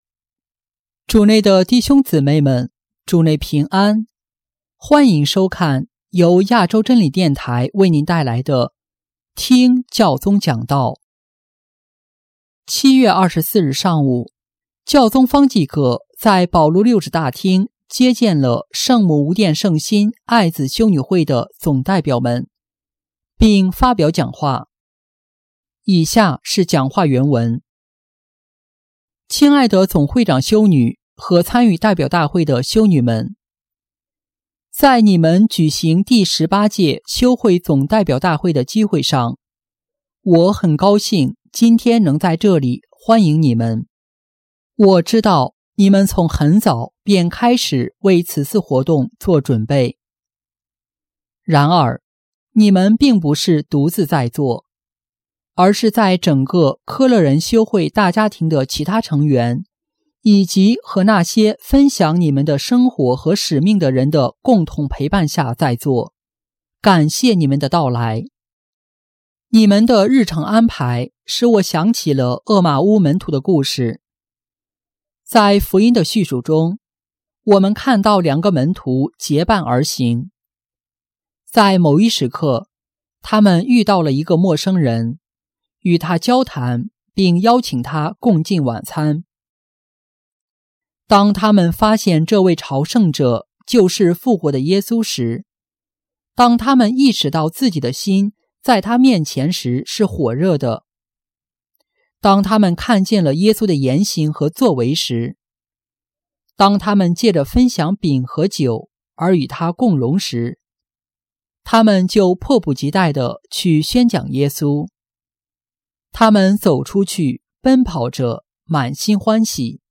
7月24日上午，教宗方济各在保禄六世大厅接见了圣母无玷圣心爱子修女会（柯乐仁修女会）的总代表们，并发表讲话。